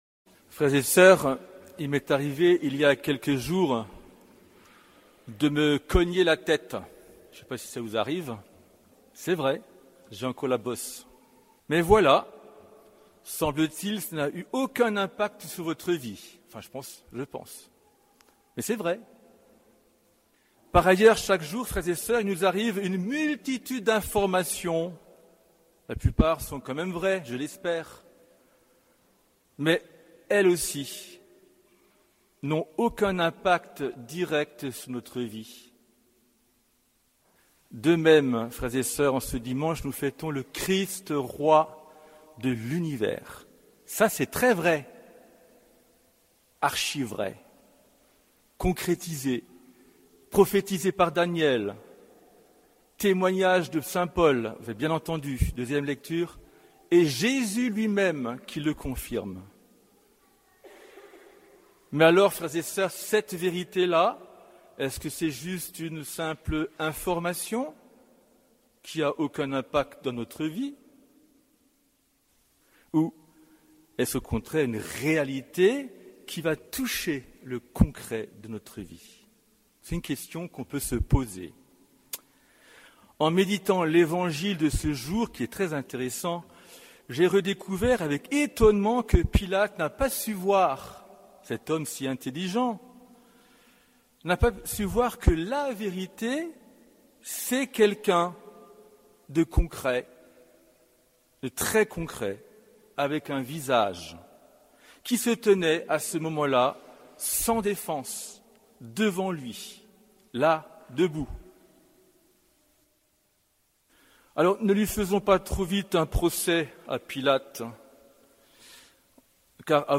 Homélie de la solennité du Christ, Roi de l’Univers